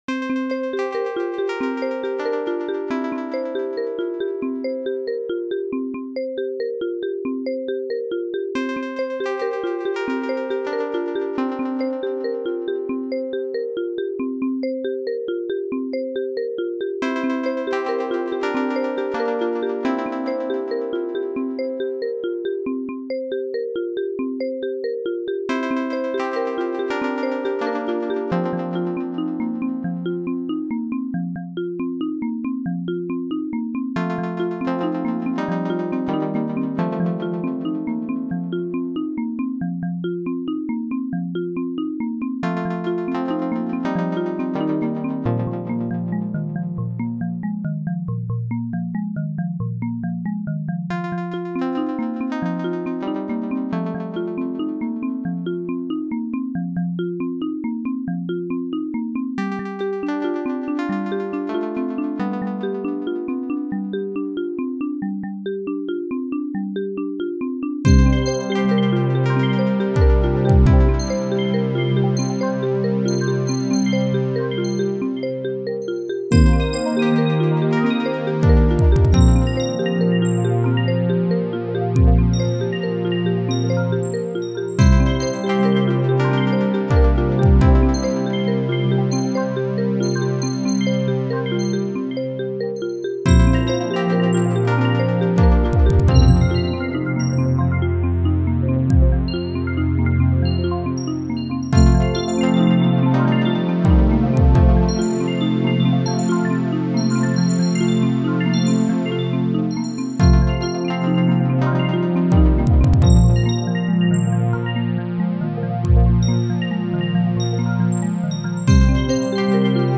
• Flow modular additive software synthesizer
The song is unusual: it has a 13/8 part overlaid on top of a 4/4 part, making for a very unusual, kind of sloppy or loose rhythm.
Obviously it's not a particularly complex song, and the second half is just a repeat and elaboration of the first half.